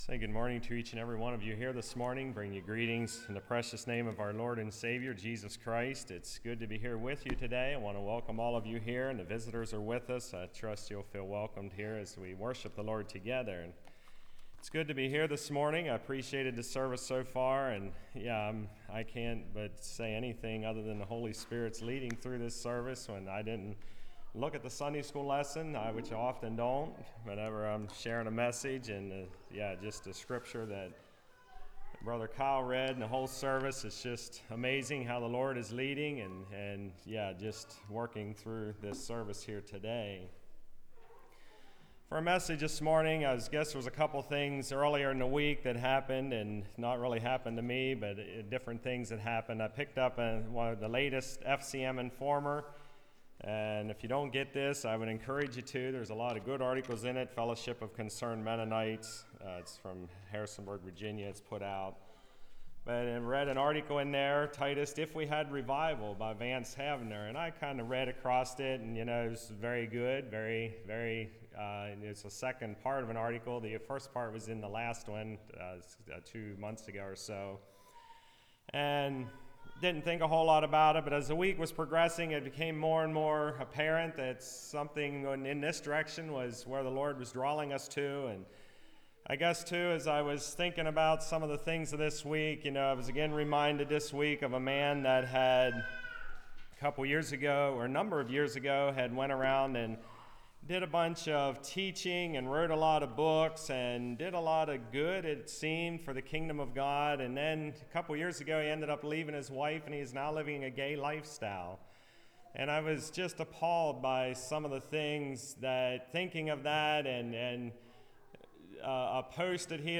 Passage: 1 John 2:12-17 Service Type: Message Bible Text